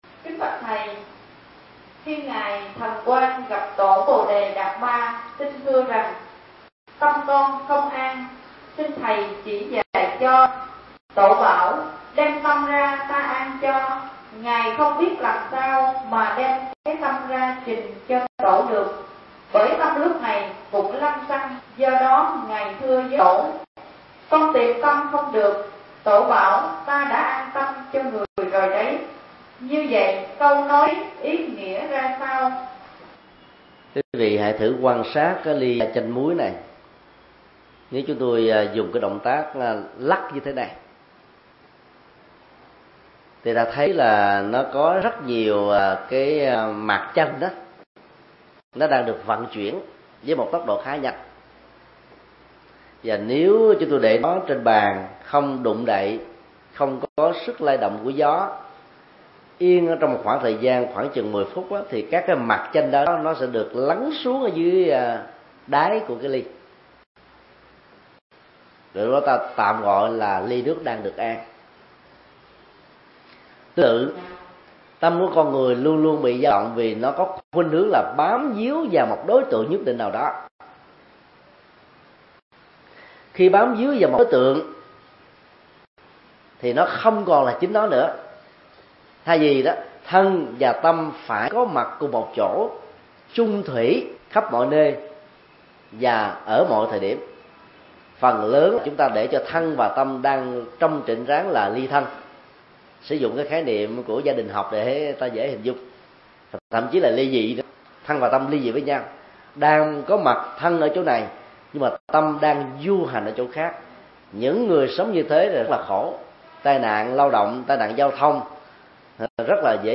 Vấn đáp: Làm chủ thân và tâm như thế nào – Thích Nhật Từ